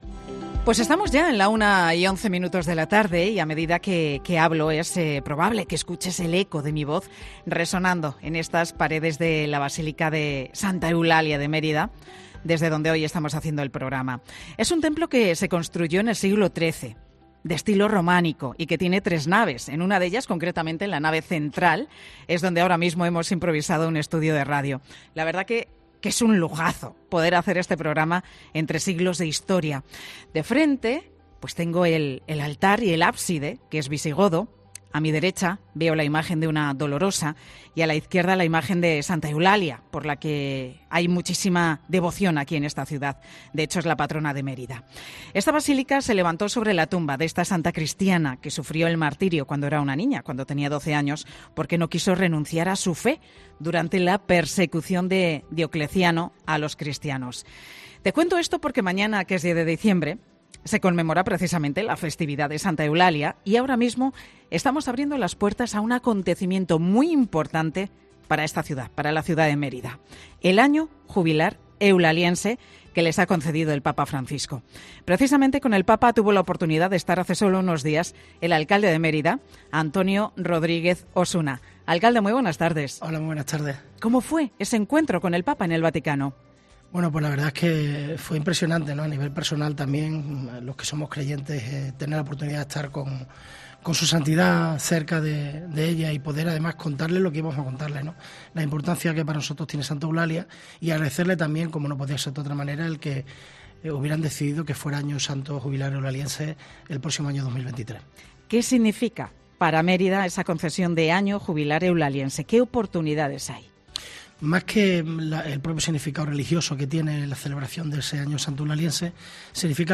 Entrevistamos en 'Mediodía COPE' al alcalde de la ciudad extremeña